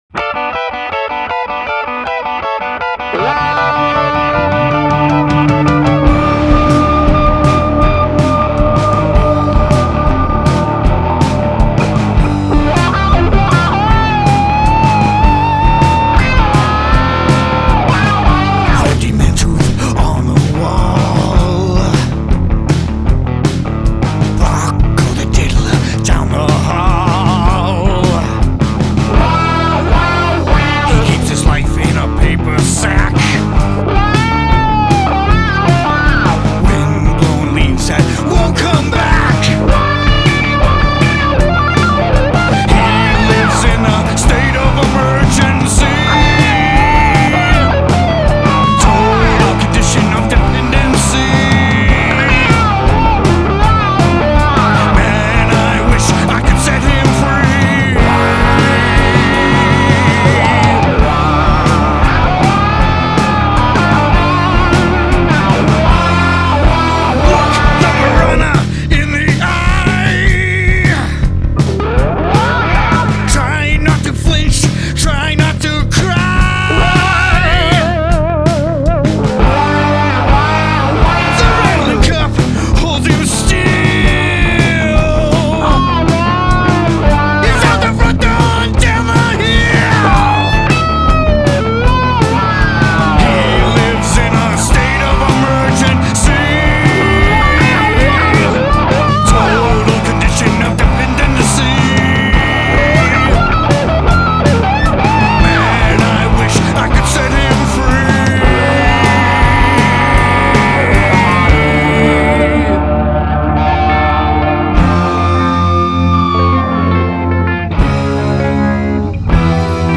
Guest Vocals